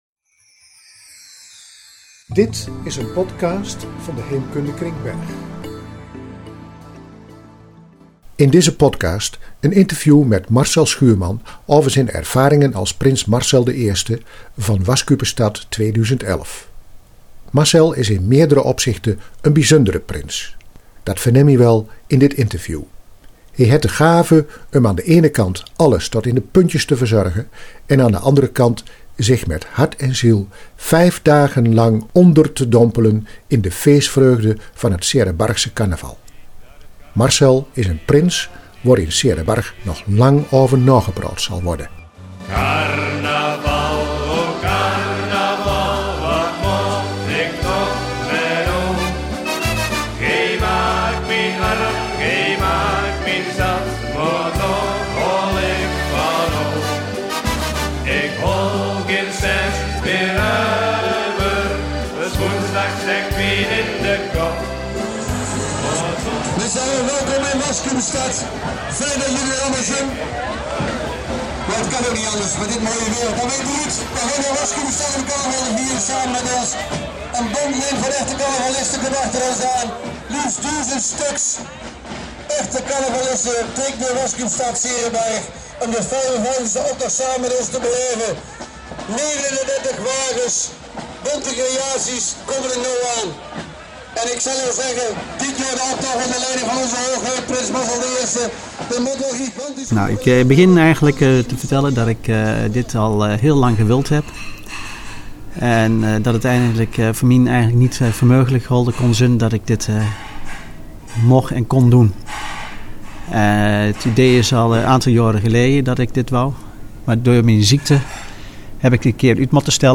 In deze podcast het interview met